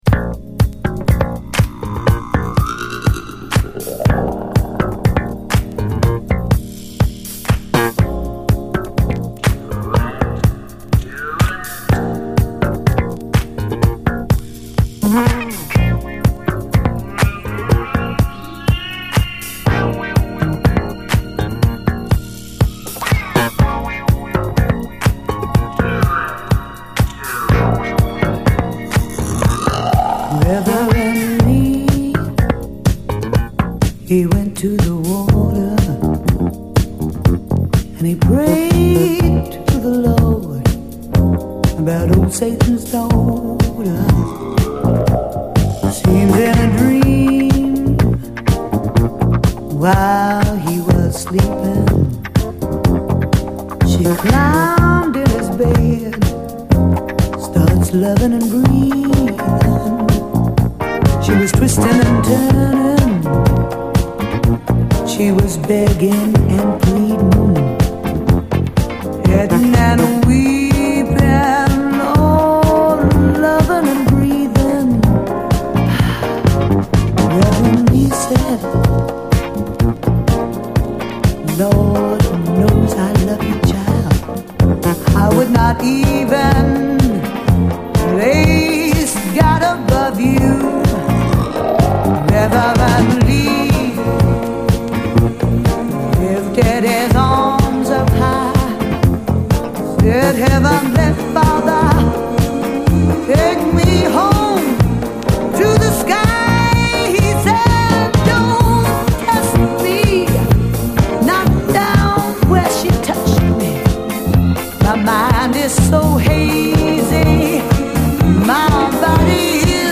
SOUL, 70's～ SOUL, DISCO
白人夫婦のディスコ・プロジェクト！